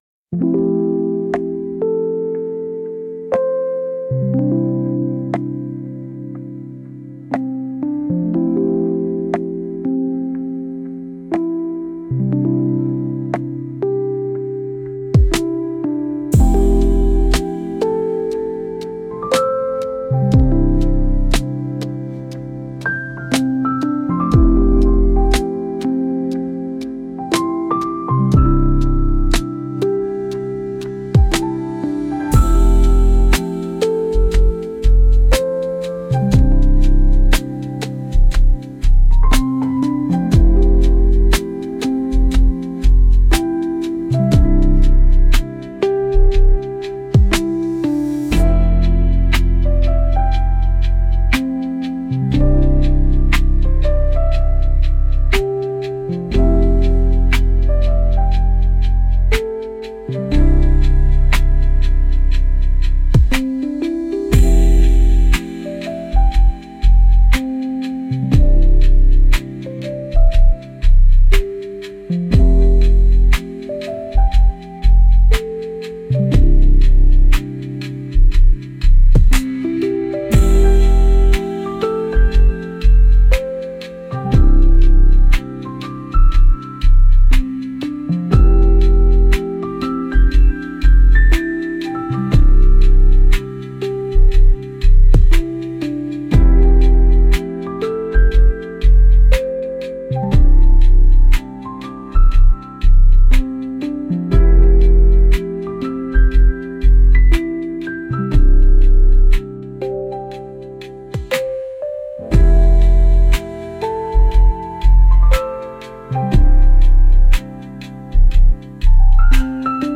Genre: LoFi Mood: Chill Editor's Choice